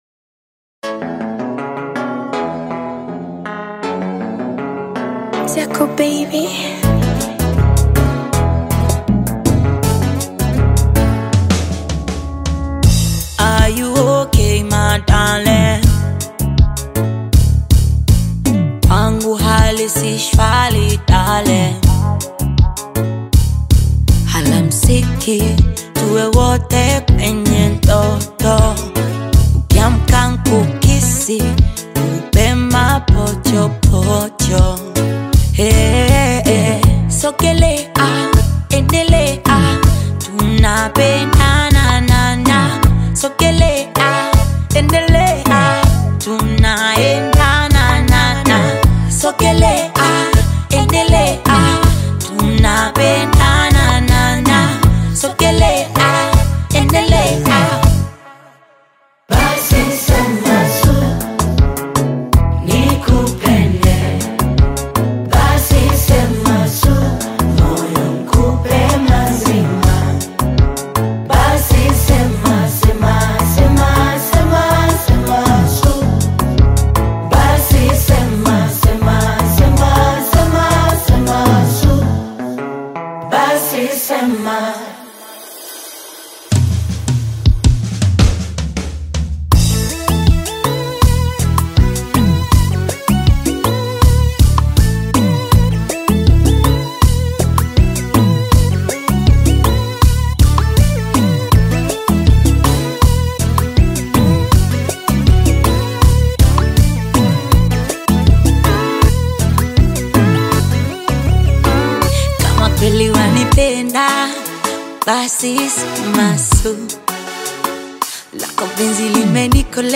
Tanzanian Bongo Flava